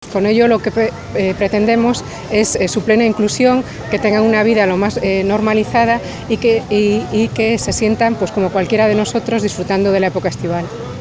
en palabras de la viceconsejera (archivo mp3) formato MP3 audio(0,42 MB).